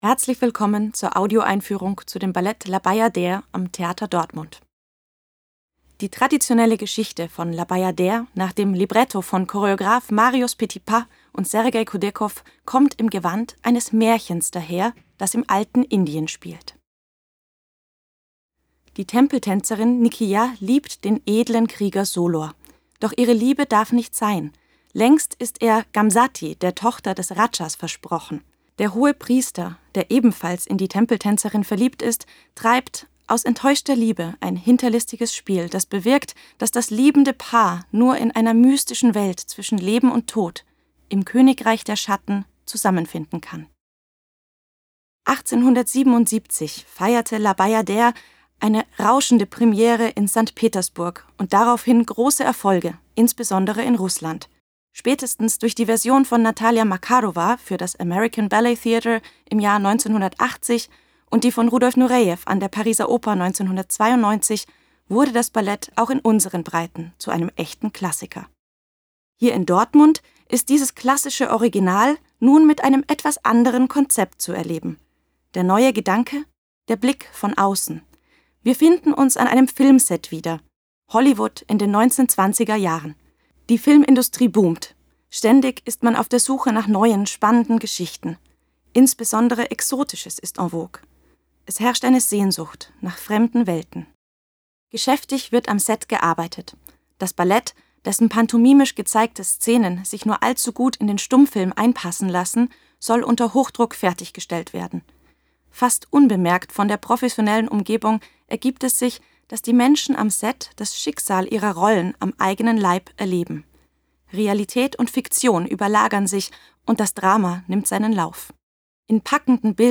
tdo_Audioeinfuehrung__La_Bayadere.mp3